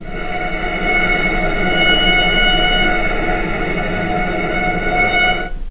vc_sp-F#5-pp.AIF